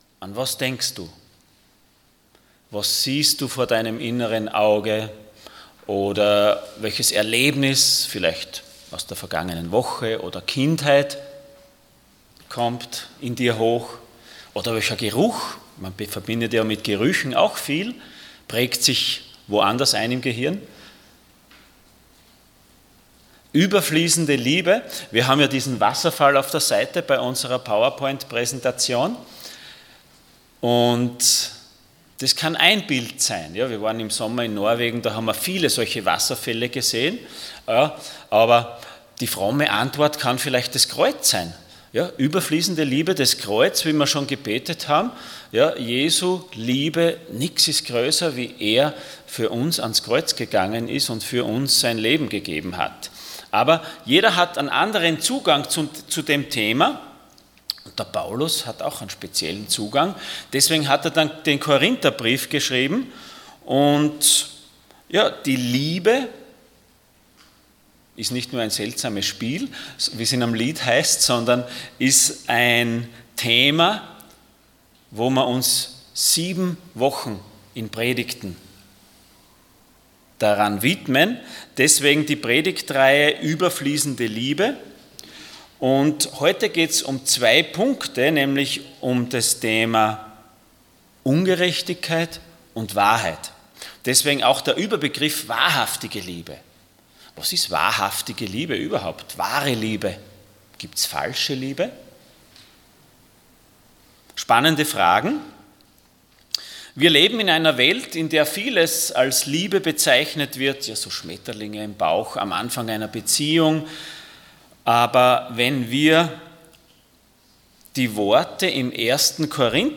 Korinther 13,6 Dienstart: Sonntag Morgen Wahrhaftige Liebe Themen: Liebe , Ungerechtigkeit , Wahrheit « Überfließende Liebe Ist die Liebe naiv?